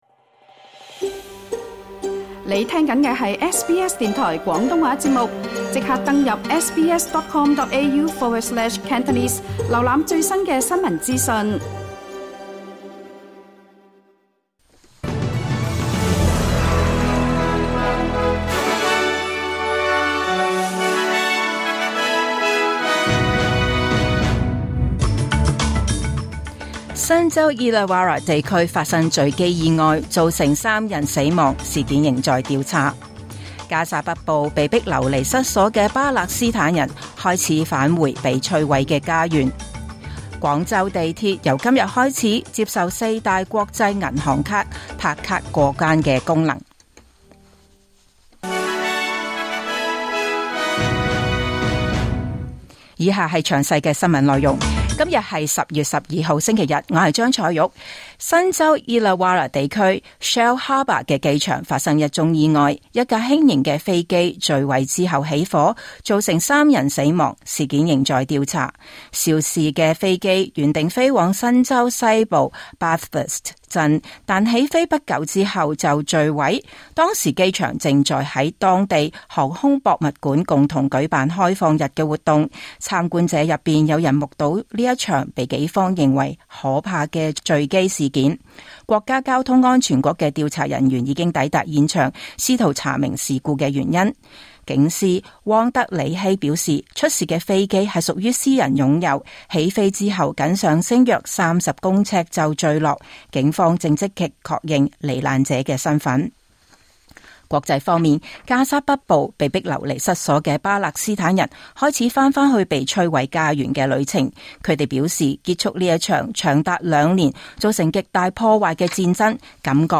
2025 年 10 月 12日 SBS 廣東話節目詳盡早晨新聞報道。